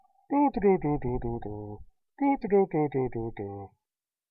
doodeedoo tune (two repetitions)
Apologies for poor sound quality.  Air conditioner in room, bad microphone.